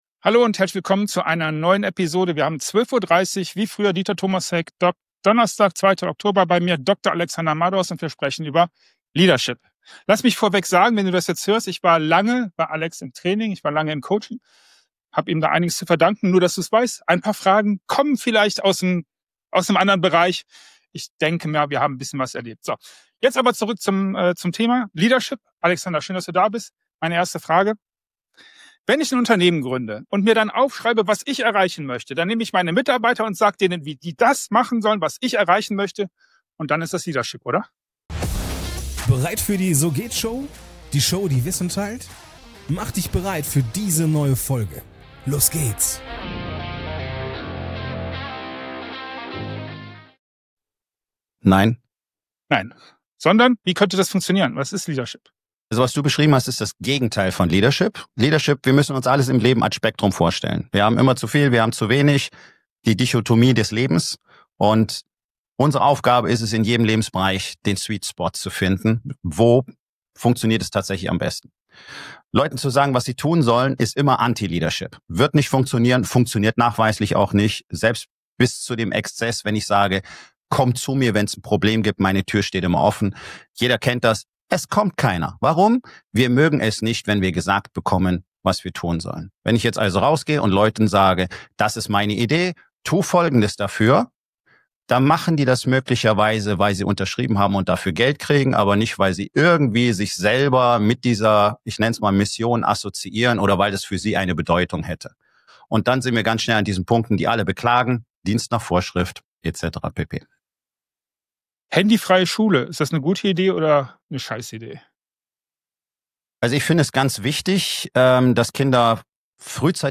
Er teilt klare Worte über moderne Irrtümer rund um Männlichkeit, Ehrlichkeit, Loyalität und Sinn – und warum wir wieder lernen müssen, zu sprechen, statt uns zu verstecken. Ein Gespräch über Haltung, Mut und das Ende von Ausreden.
Mein Interviewgast hat 45 Sekunden für seine Antwort.
Keine Nachbearbeitung, keine zweiten Versuche – was gesagt wird, bleibt.